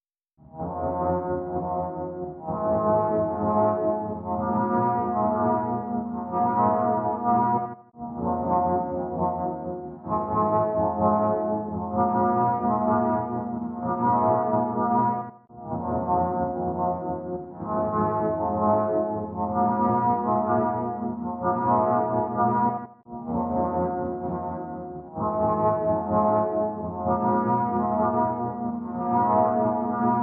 synthe_srx_studio_midi_long_01.wav